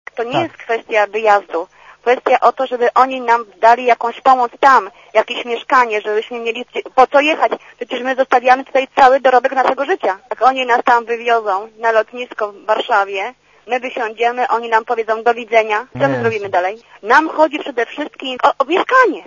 Potwierdza to w rozmowie z Radiem ZET jedna z Polek, która mieszka w Bagdadzie.
Komentarz audio
polkazbagdadu.mp3